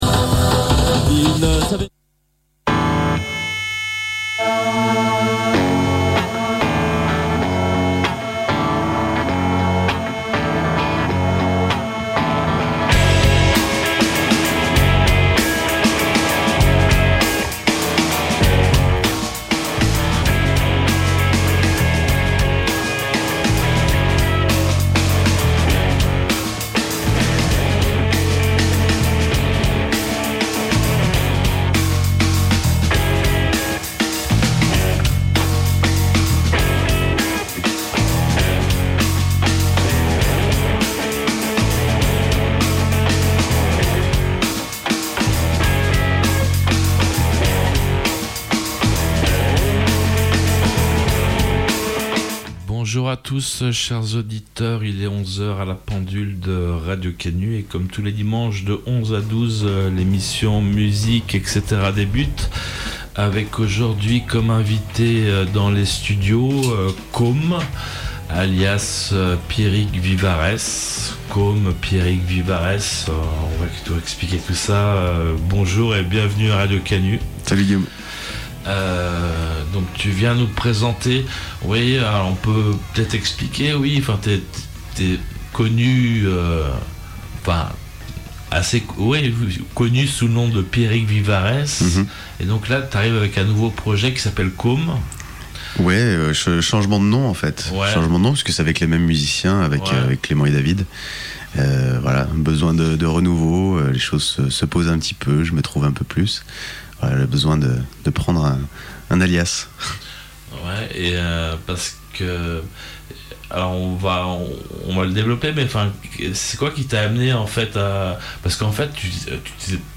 Ce dimanche 20 mai 2018 de 11 à 12 et en direct